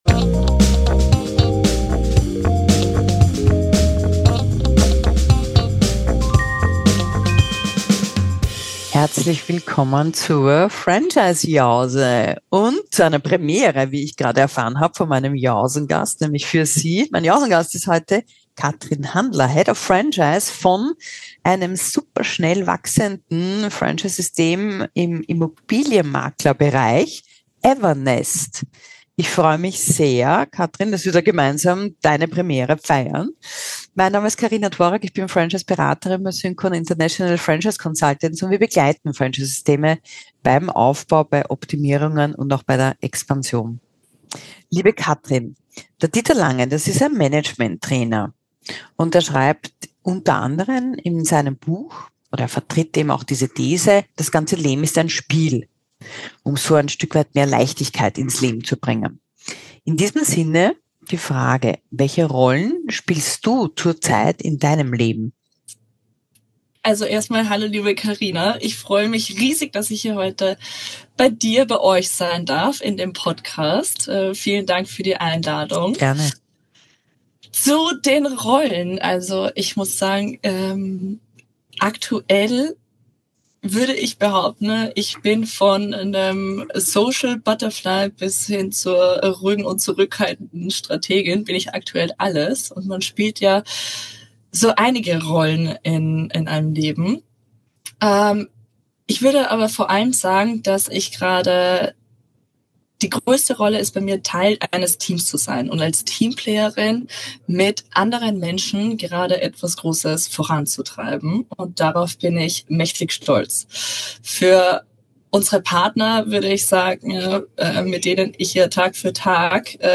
Ein Gespräch über Technologie, Citypartner, Multi-Listing, Corporate Culture und Mentoring